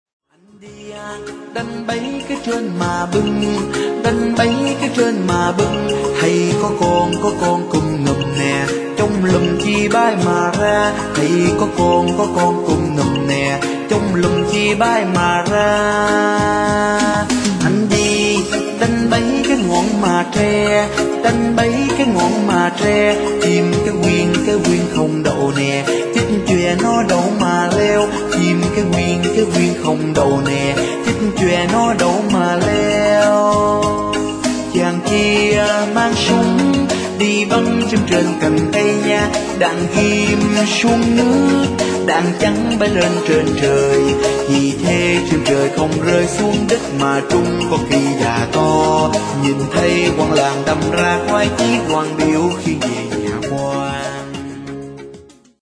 Dân ca Nam Bộ